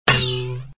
HolyHandGrenadeBounce.mp3